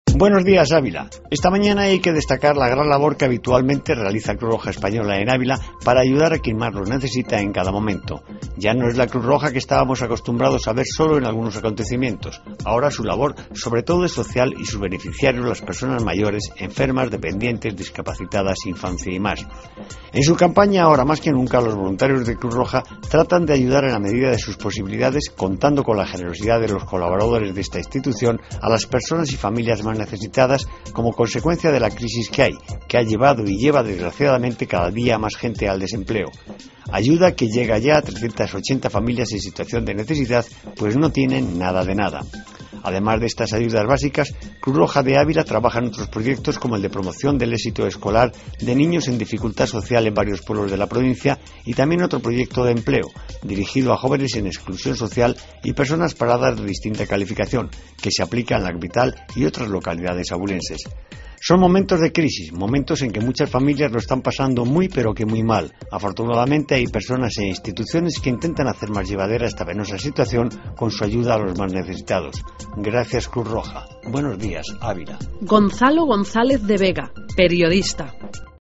AUDIO: Comentario de actualidad en la Mañana de Cope